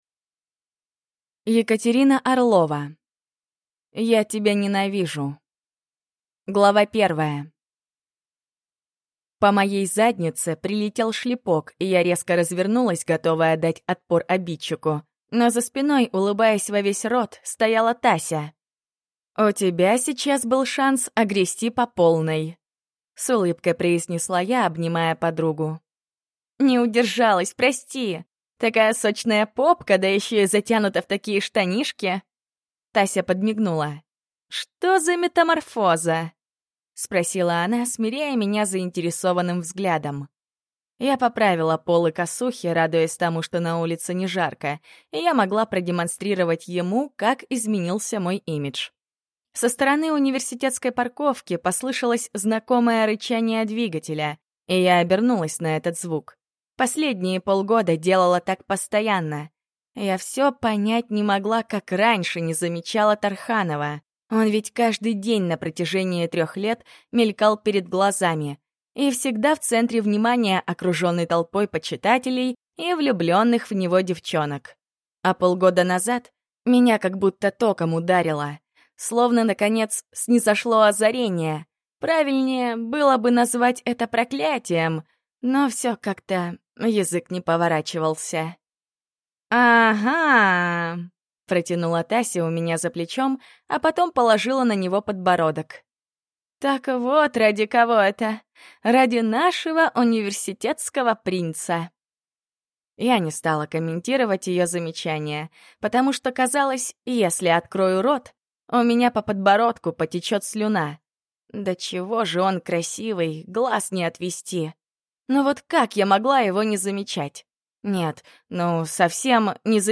Аудиокнига Я тебя ненавижу | Библиотека аудиокниг
Прослушать и бесплатно скачать фрагмент аудиокниги